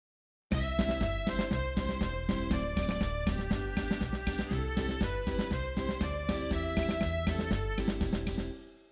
Pasodoble Stellodia